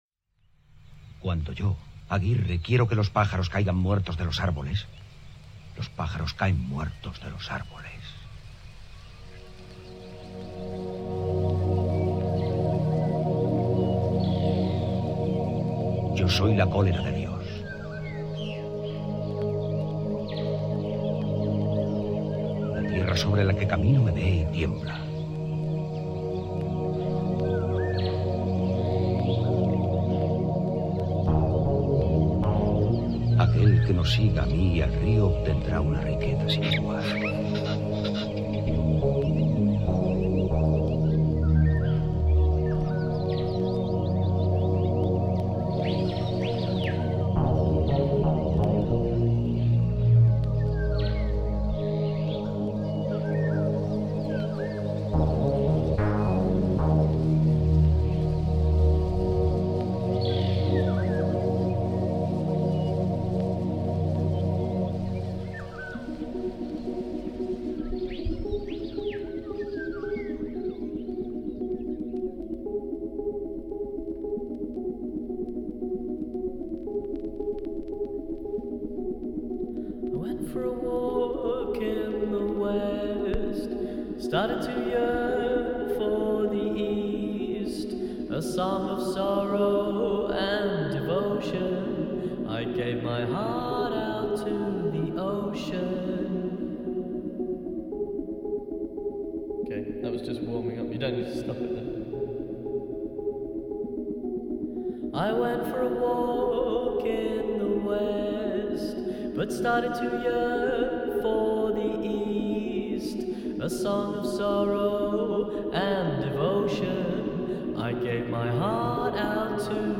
un cuidadísimo viaje electrónico